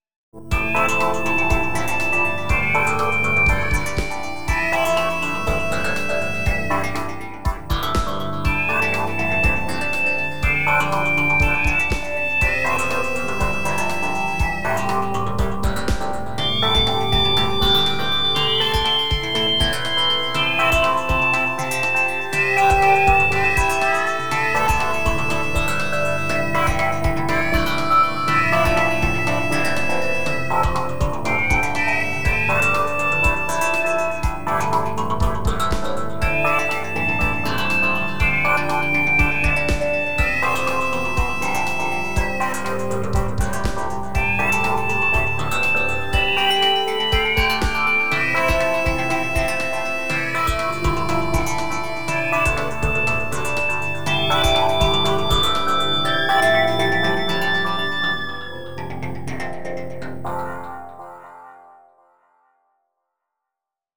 インストゥルメンタルエレクトロニカショート
BGM